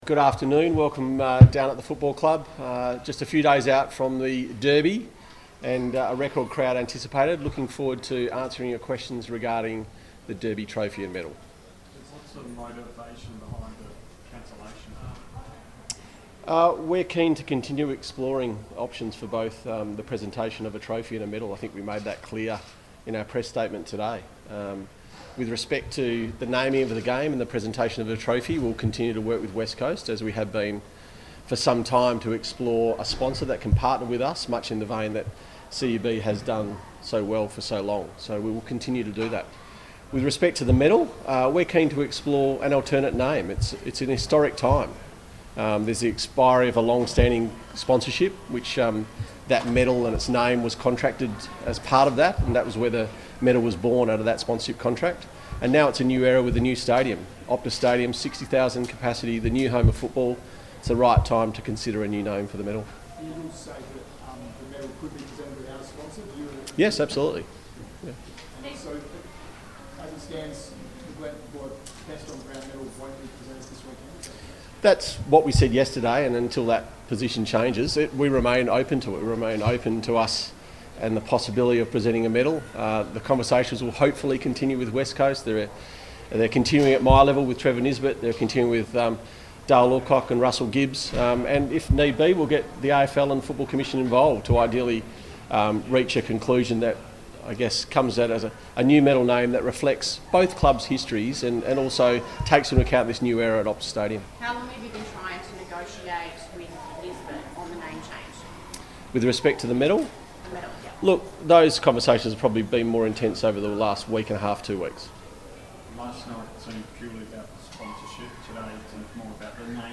Media Conference